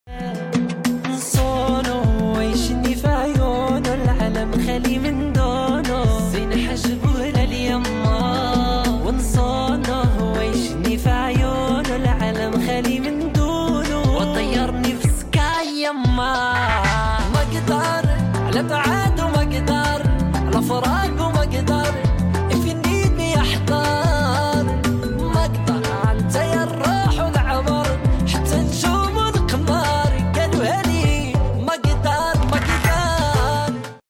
اول مصري يغني مغربي 😂❤🇲🇦 sound effects free download